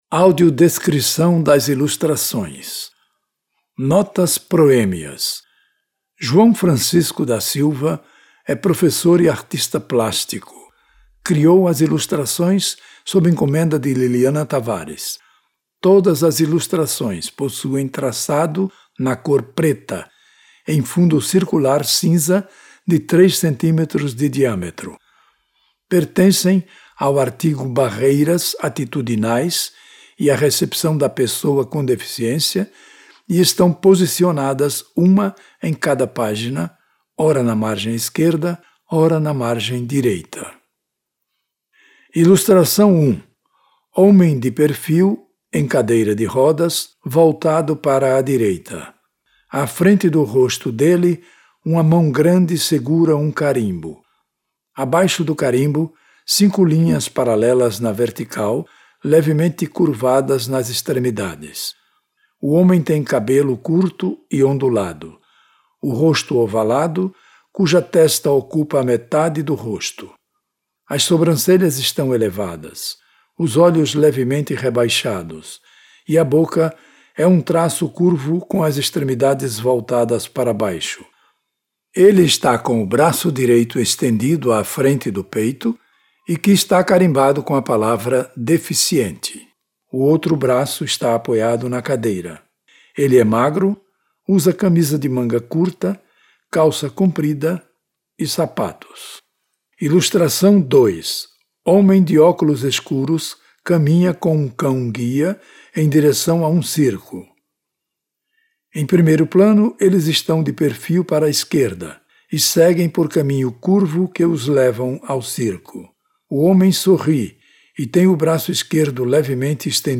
AUDIODESCRICAOILUSTRACOES.mp3